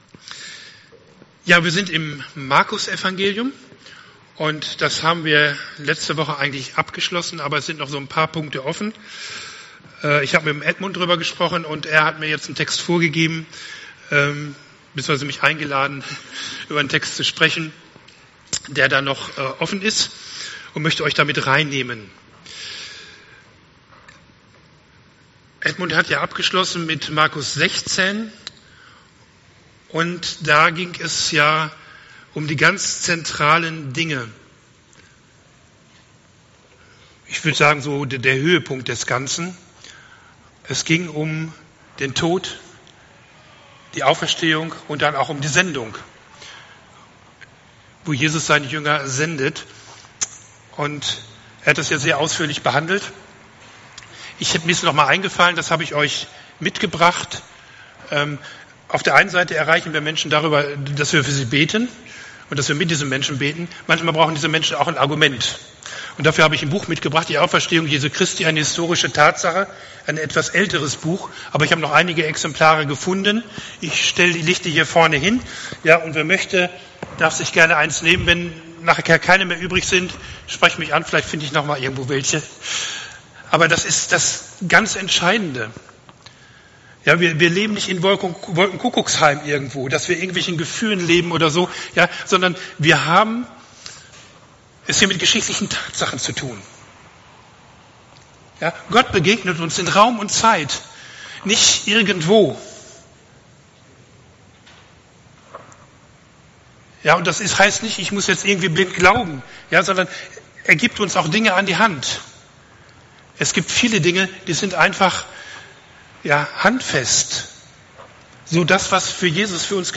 Predigt 08.08.2021